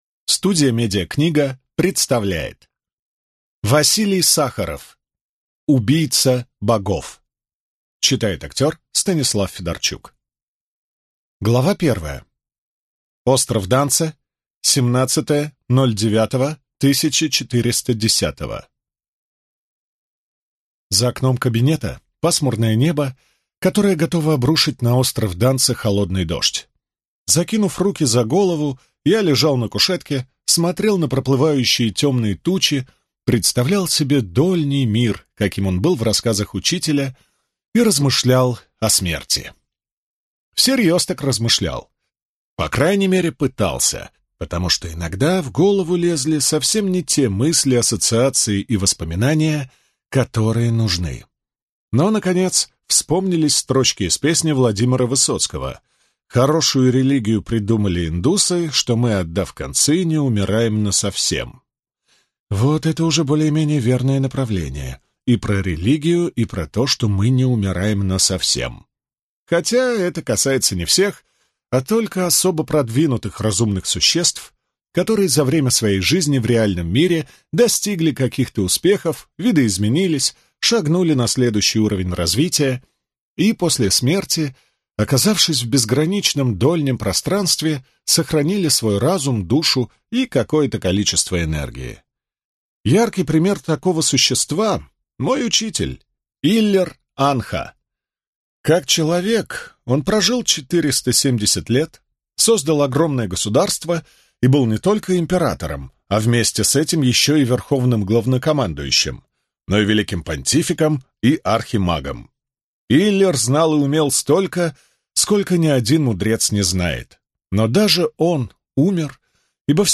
Аудиокнига Убийца Богов | Библиотека аудиокниг